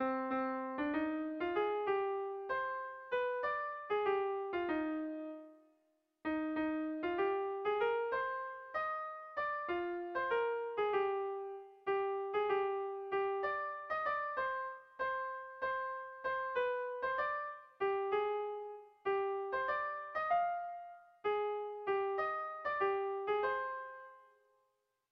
Erlijiozkoa
Zortziko txikia (hg) / Lau puntuko txikia (ip)
ABDE